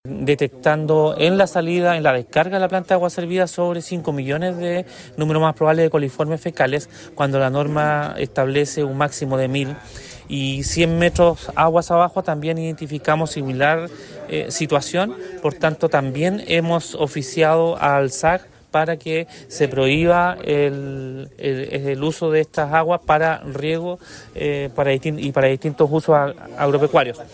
De acuerdo a lo señalado por el seremi de Salud, Andrés Cuyul, se generó un decreto para prohibir la utilización de dichas aguas para riego o cualquier actividad agrícola.